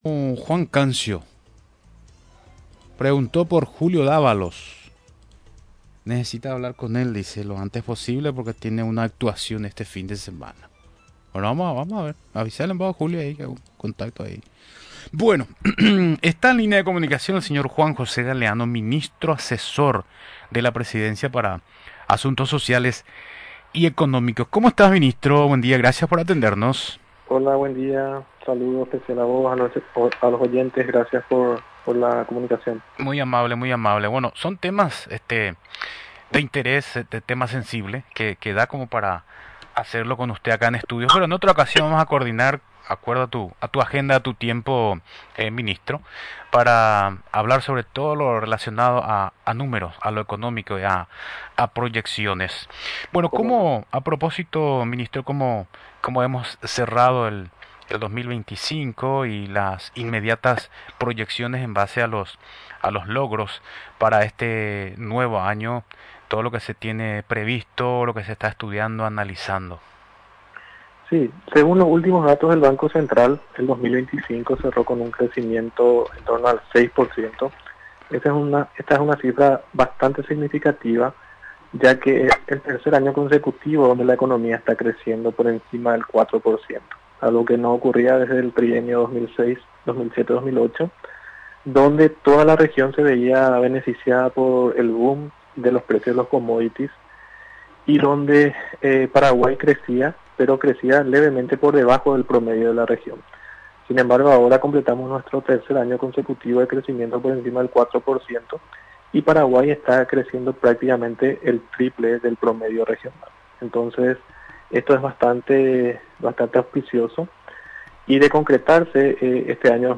Durante la entrevista en Radio Nacional del Paraguay, recordó que el proyecto de Ley de Reforma, ya fue presentado por el Poder Ejecutivo al Congreso Nacional.